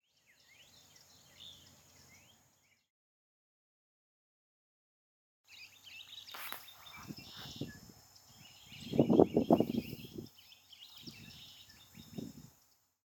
Couch's Kingbird